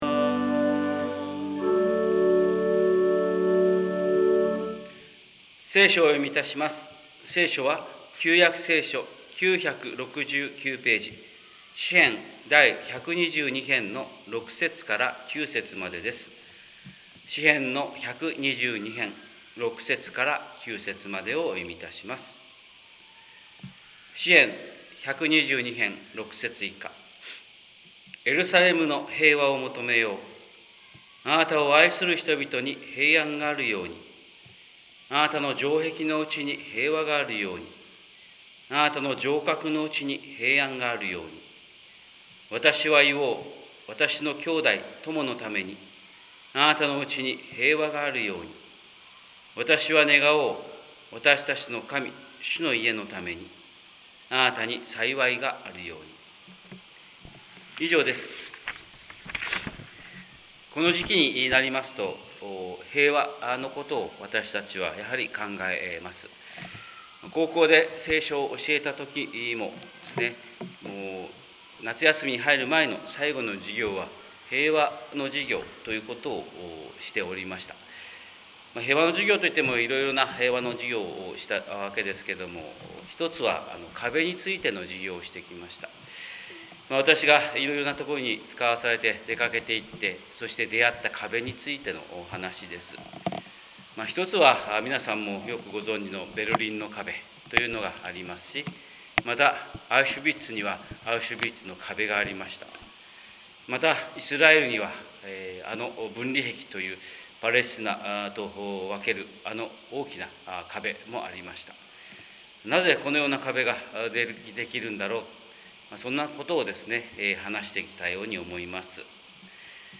神様の色鉛筆（音声説教）: 広島教会朝礼拝210802